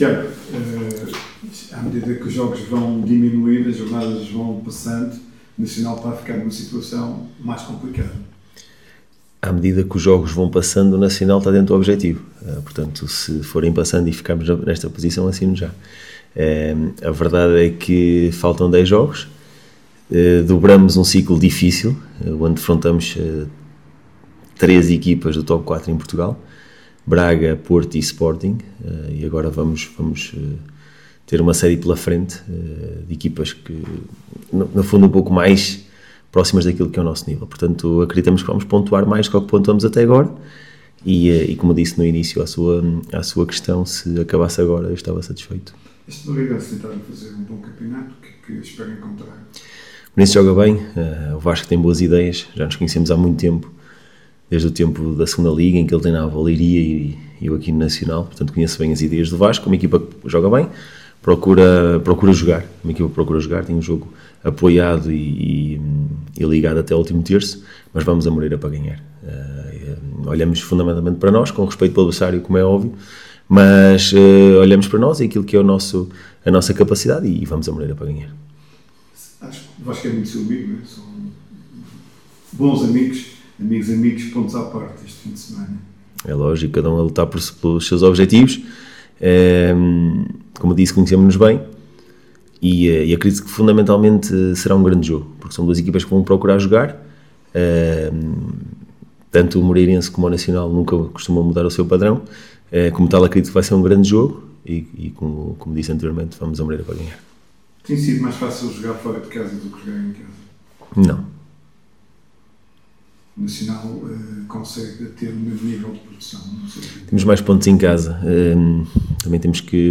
Na conferência de imprensa de antevisão à partida